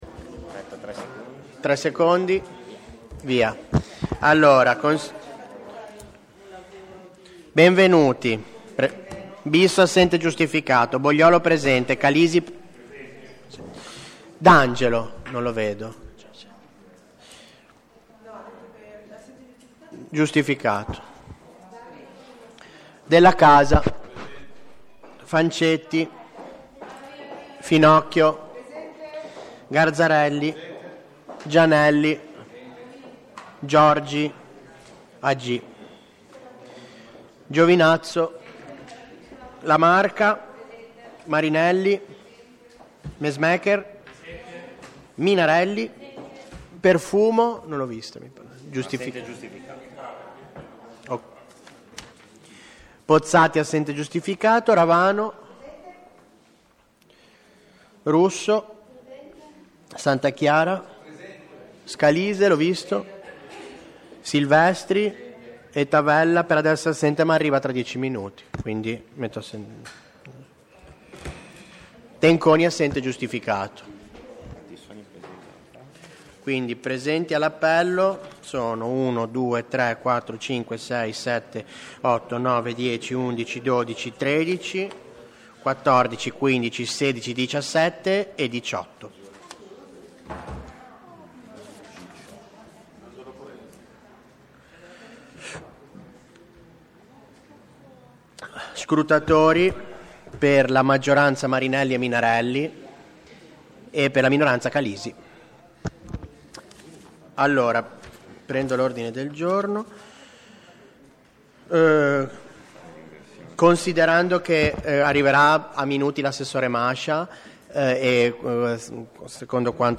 Luogo: Via Pinasco, 7 genova
Audio seduta